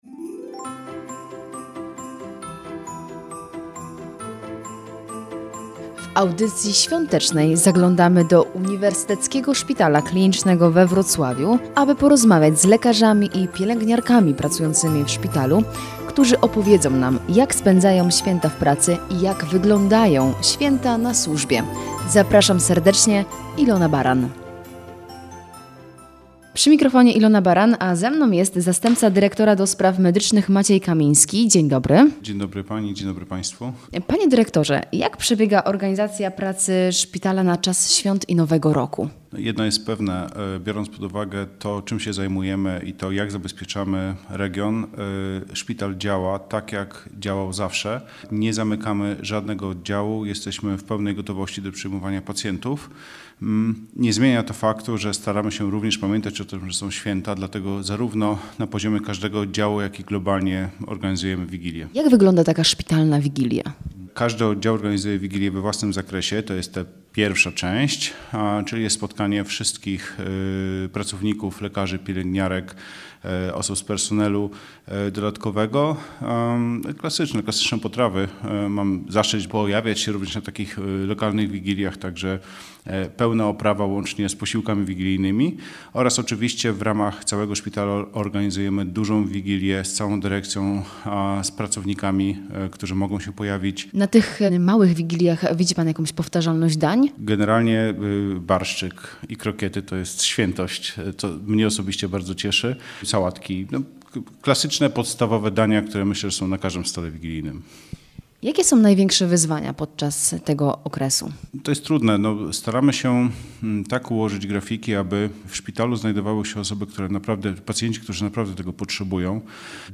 W audycji „Święta na służbie” zaglądamy do Uniwersyteckiego Szpitala Klinicznego we Wrocławiu, aby porozmawiać z lekarzami i pielęgniarkami pracującymi w szpitalu, którzy opowiedzą nam, jak spędzają święta w pracy? Czy czas świąt wpływa na atmosferę?